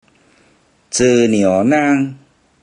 Click each Romanised Teochew word to listen to how the Teochew word is pronounced.
zer3nio1nan1  or  zer3nio1